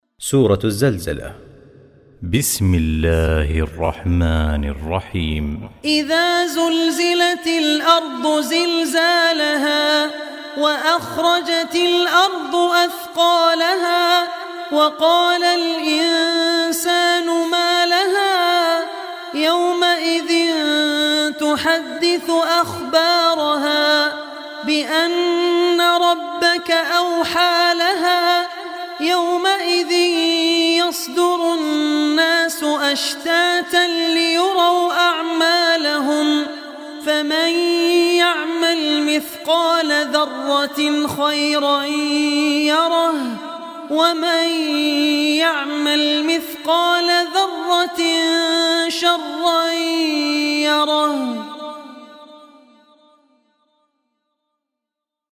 Surah Zalzalah, listen online mp3 tilawat / recitation in Arabic in the beautiful voice of Sheikh Abdur Rahman Al Ossi.